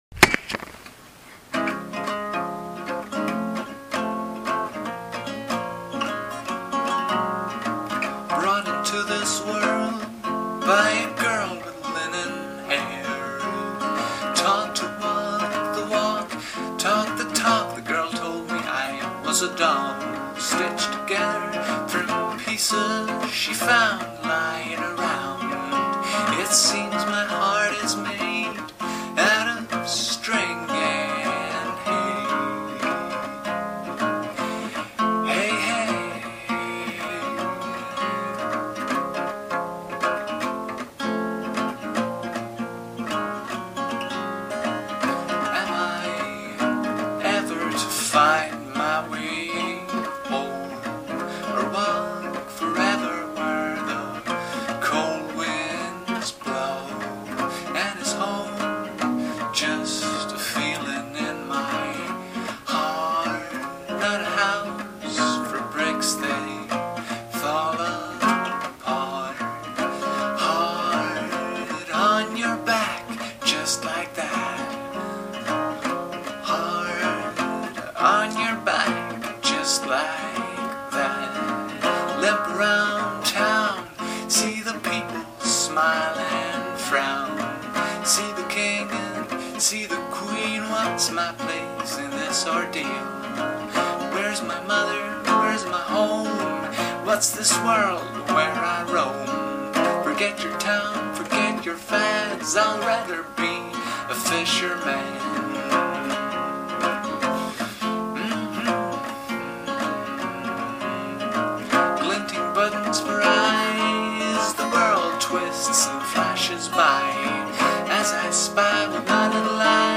Võtsin diktofoniga, et lugu "salve" jääks, kuniks aega korralikult salvestada, aga tuli niigi täitsa kenasti. Pealegi, diktofonisahin on uus vinüülisahin. Ja mis kõige vahvam – loo lõpu aitas sisse laulda ootamatu külalisesineja.